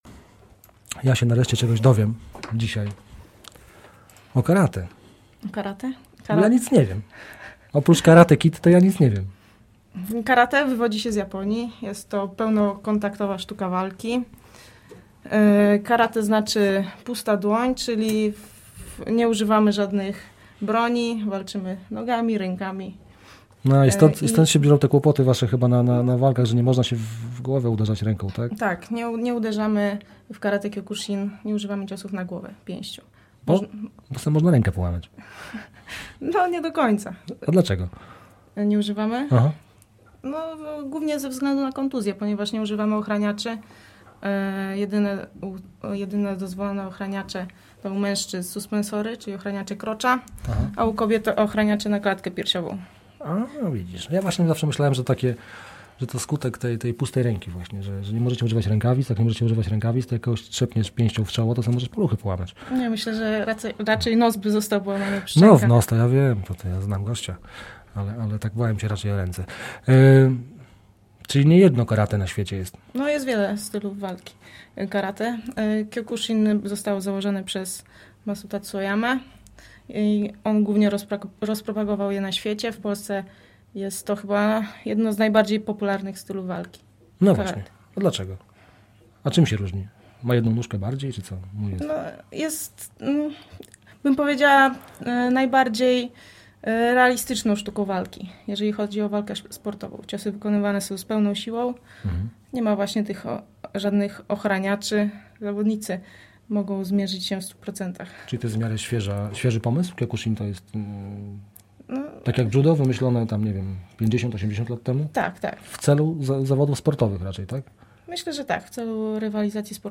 wywiad – niecodziennik
W studio Radia Azyll jest dość wesoło!